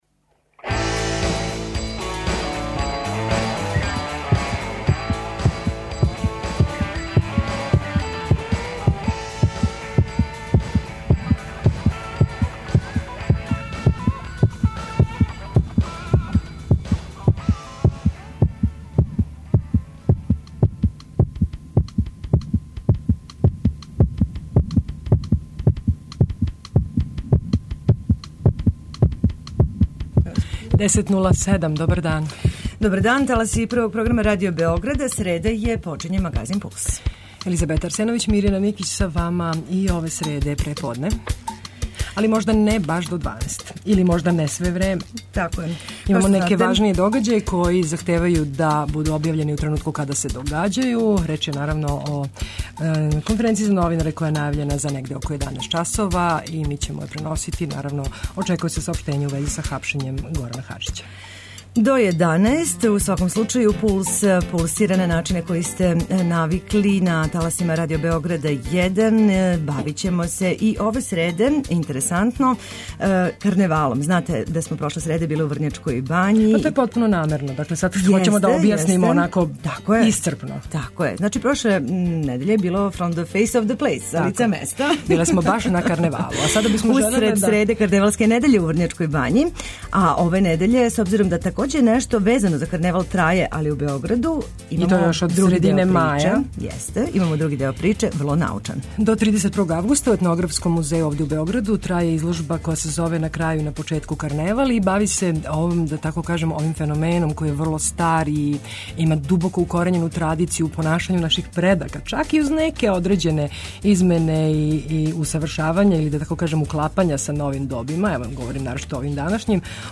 Преносили смо конференцију за медије председника Бориса Тадића, а наши репортери преносили су вести које су стизале, у вези са хапшењем Хаџића.